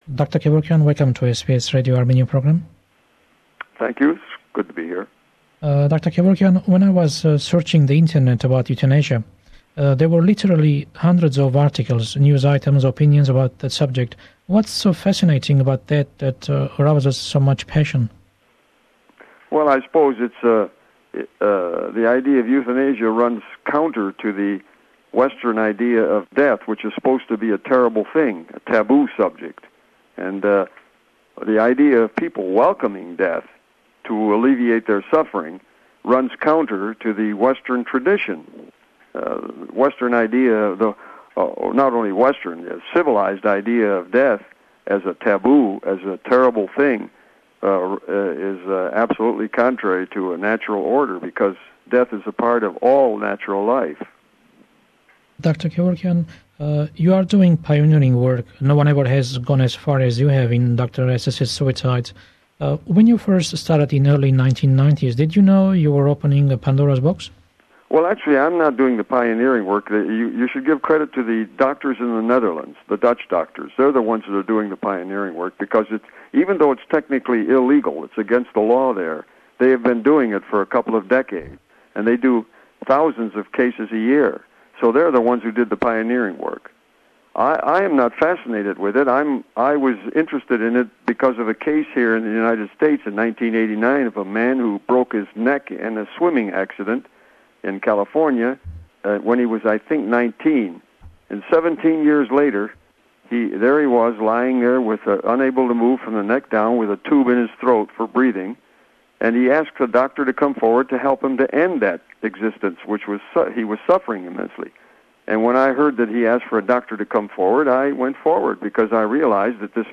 Celebrating 40 years of SBS Armenian program. This week we bring you an interview broadcast September 24, 1997 with Dr Jack Kevorkian.